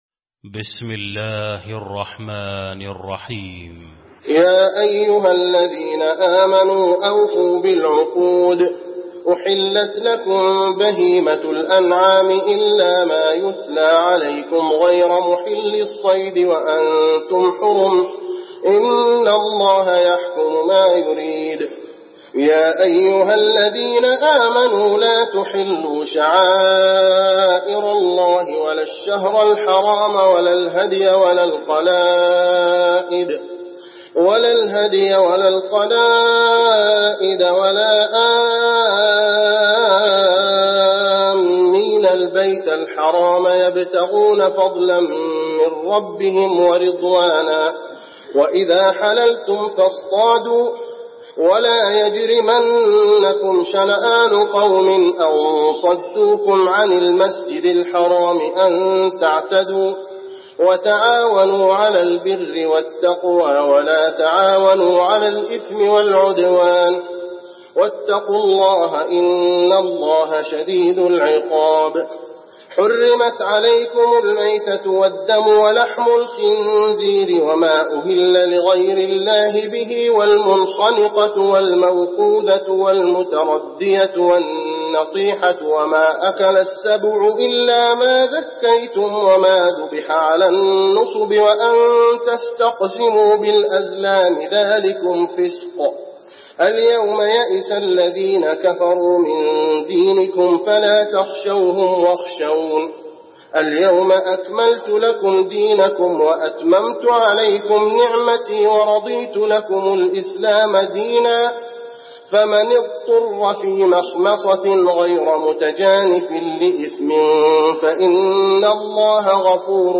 المكان: المسجد النبوي المائدة